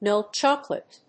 アクセントmílk chócolate